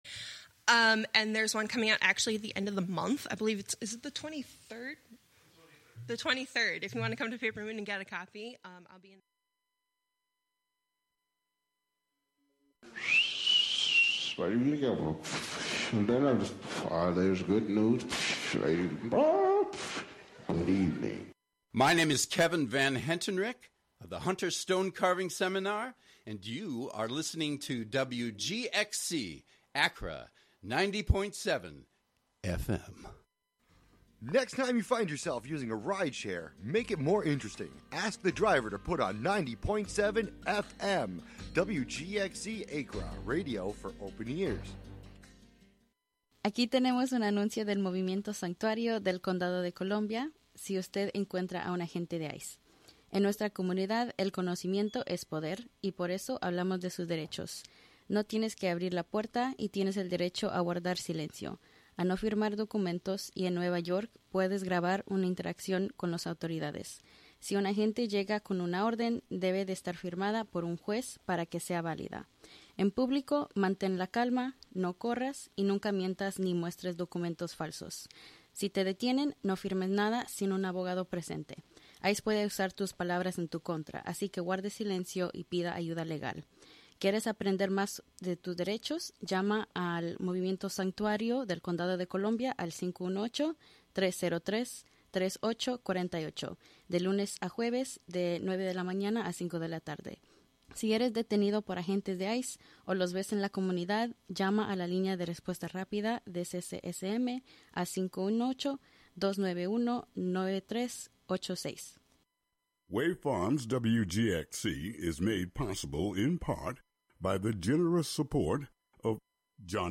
Honoring the central importance of music and sound in decolonization practices. Amplifying Indigenous worldviews, knowledge systems, and sound practices.